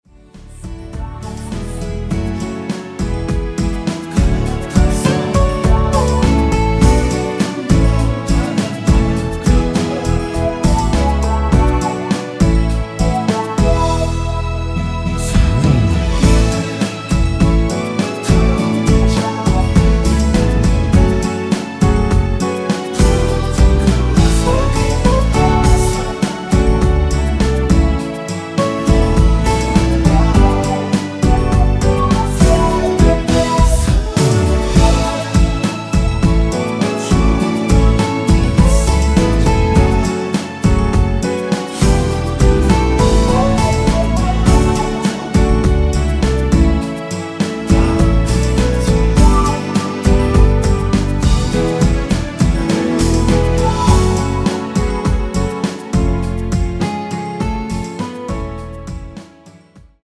앞부분30초, 뒷부분30초씩 편집해서 올려 드리고 있습니다.
곡명 옆 (-1)은 반음 내림, (+1)은 반음 올림 입니다.